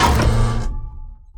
gun-turret-activate-02.ogg